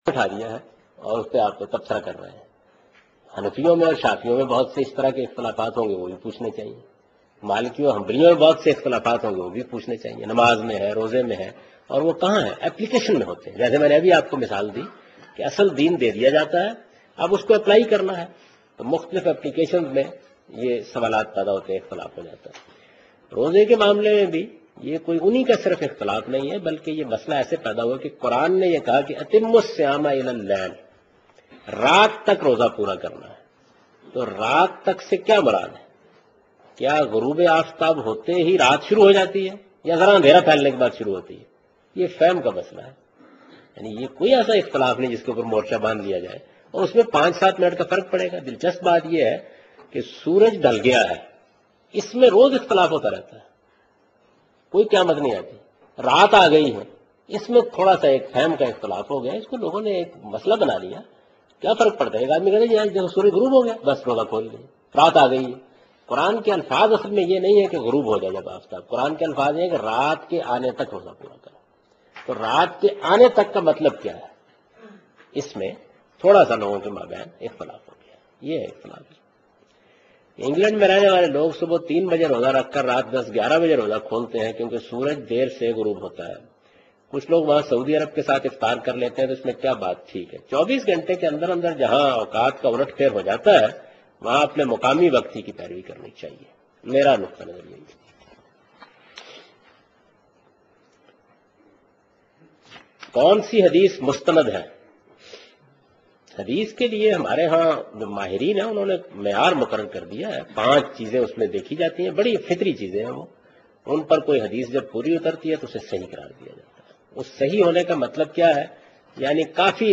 اس پروگرام میں جاوید احمد غامدی دینی مسایل کے جوابات-۳ کے متعلق بیان کر رہے ہیں